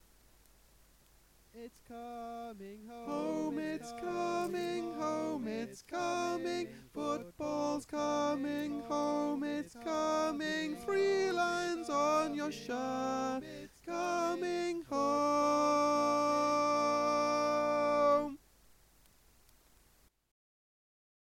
Key written in: B♭ Major
Type: Barbershop